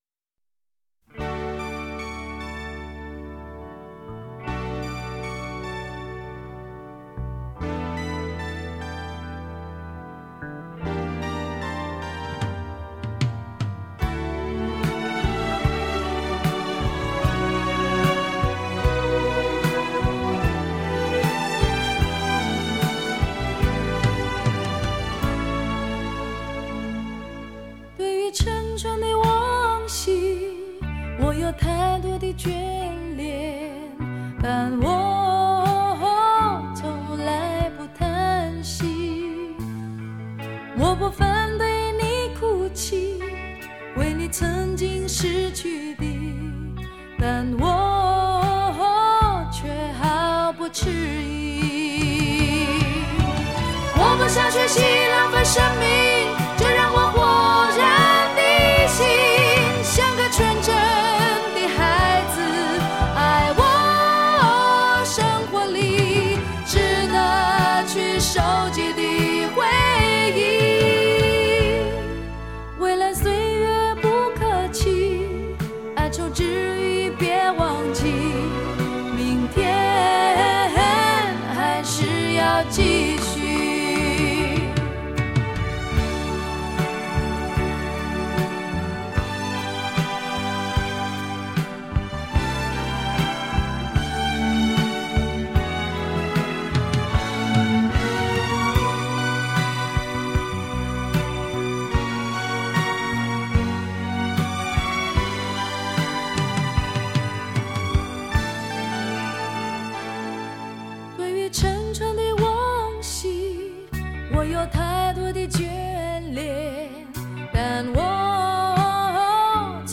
五千年摇滚女声弟人